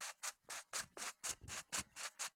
Cue Chalking.wav